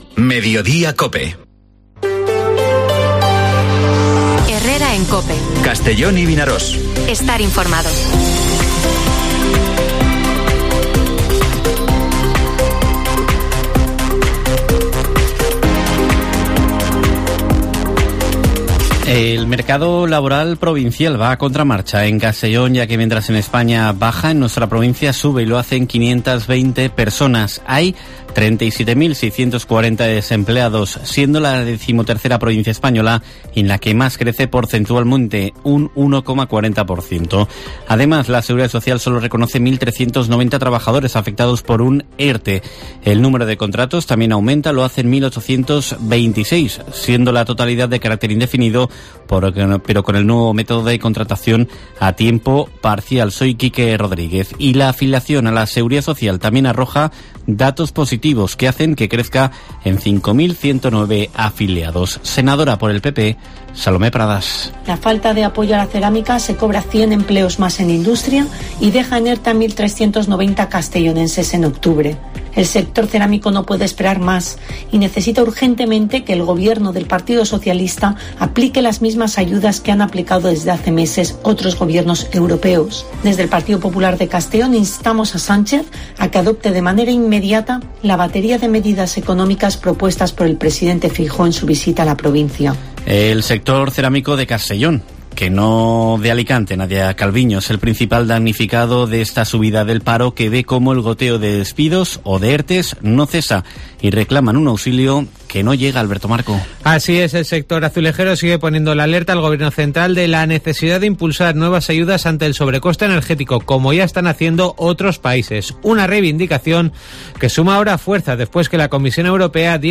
Informativo Mediodía COPE en la provincia de Castellón (03/11/2022)